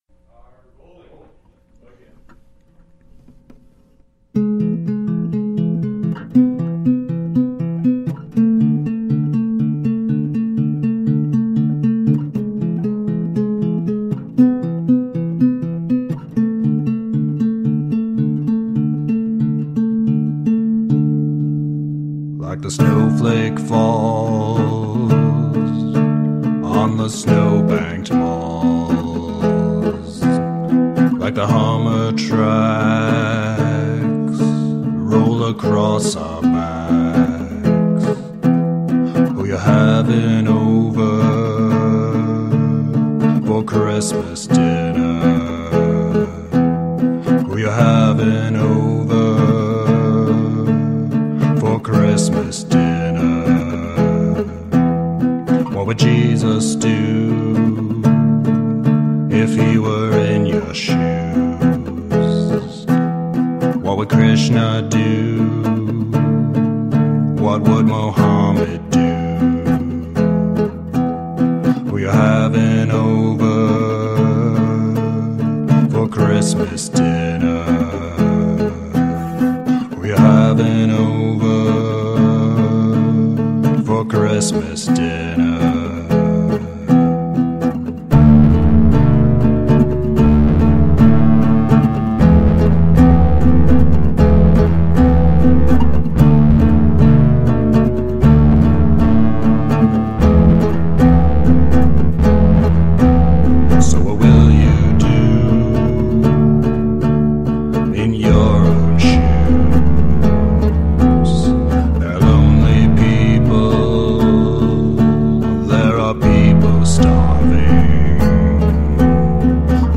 dreamy soundscapes and post-rock spaces